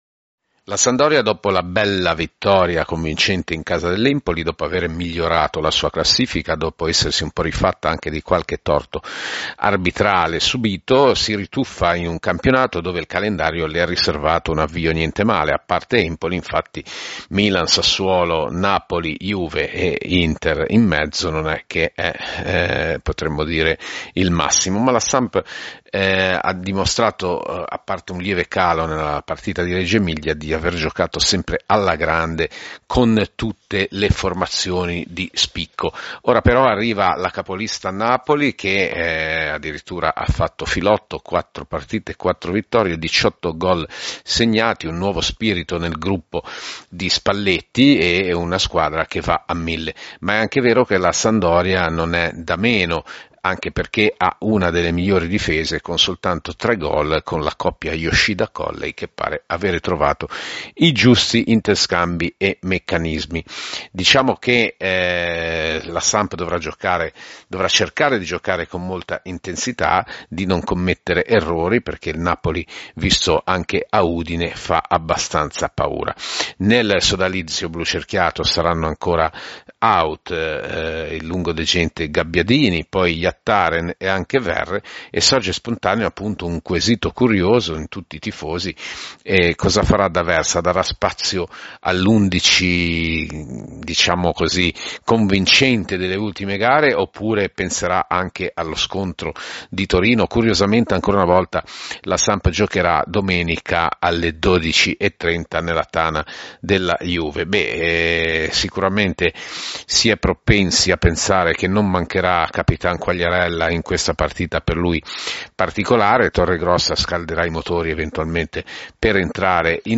commento